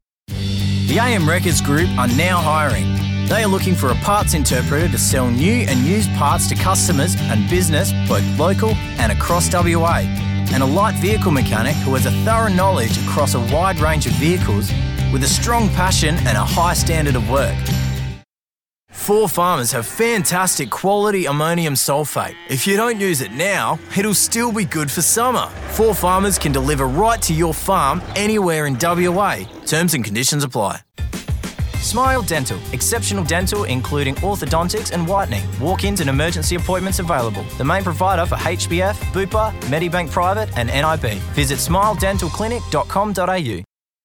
I've a versatile voice. Can I do dynamic teenagers voices or voices elegants for corporates products. All records with the profesional quality of my studio....
Dynamic, commercial, warm, friendly, and inspiring voice with different shades according to the client's needs.
1202Commercial_reel.mp3